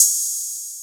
Closed Hats
15_TrapHats_SP_09.wav